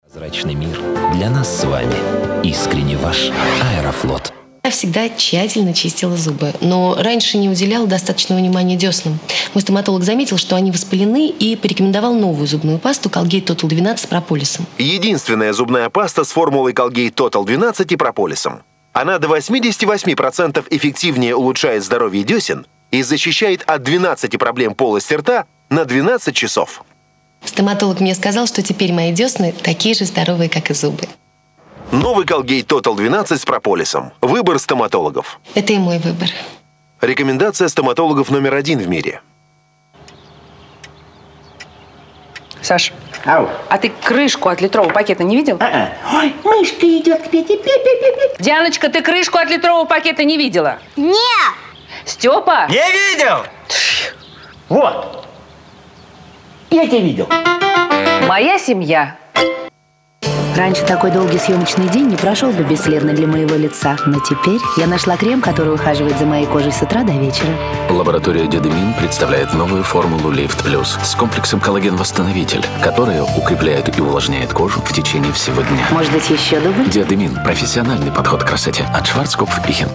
Во время того как начинаю играть, периодически появляются какие то мерзкие цифровые шумы, скрипы и шипения...